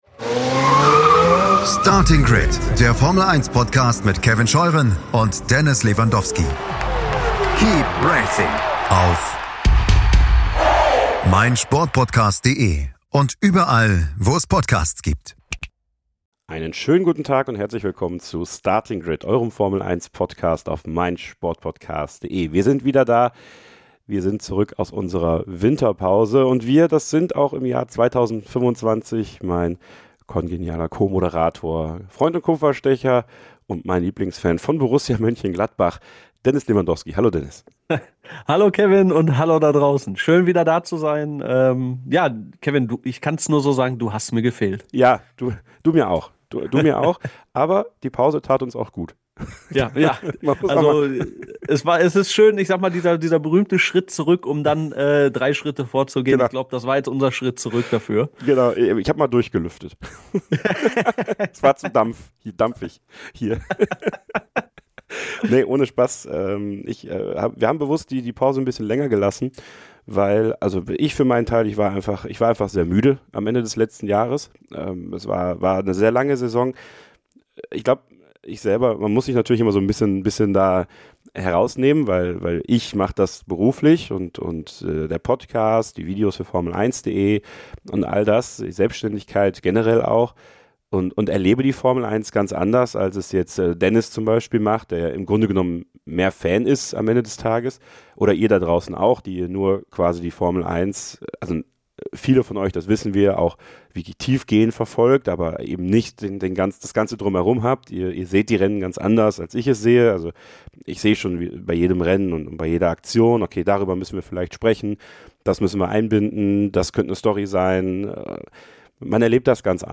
Euch erwartet ein offenes, ehrliches und unterhaltsames Gespräch.
Heute kommt ein Interview, das Wellen schlagen wird. Heiko Waßer ist DIE Stimme der Formel 1 bei RTL.